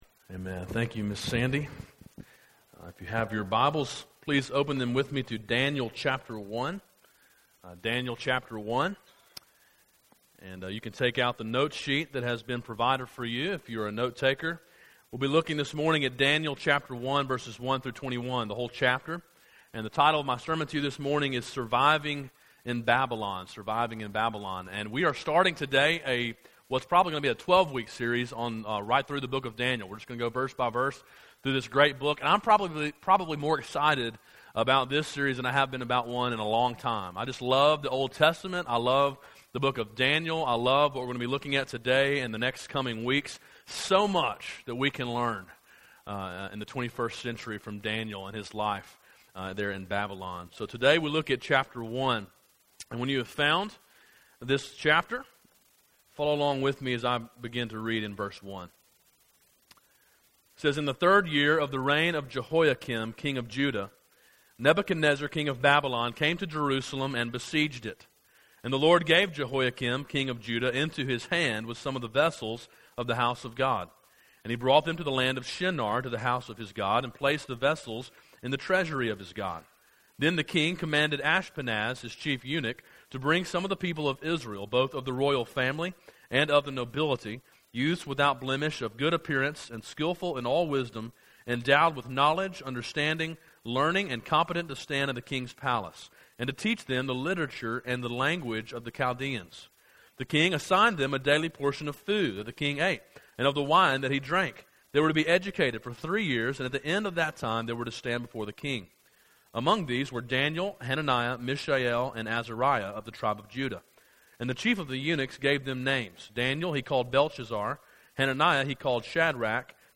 A sermon in a series on the book of Daniel.